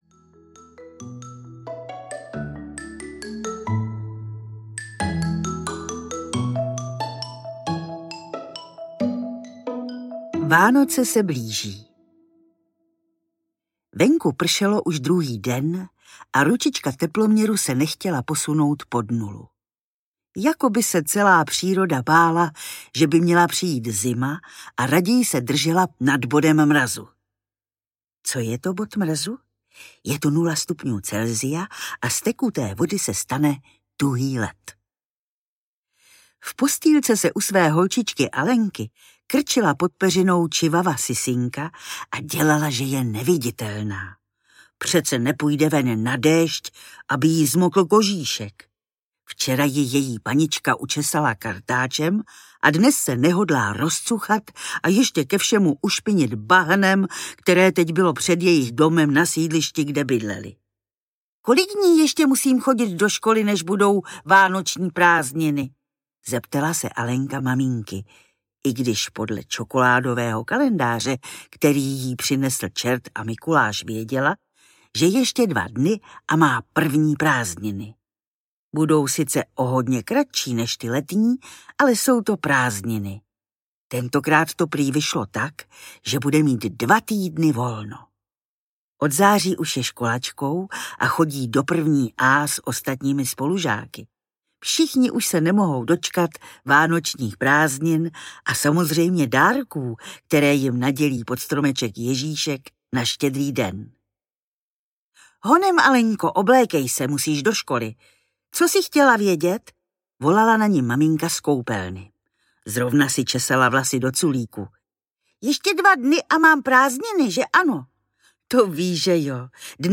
Alenka, Krakonoš a Vánoce audiokniha
Ukázka z knihy
Čte Naďa Konvalinková.
Vyrobilo studio Soundguru.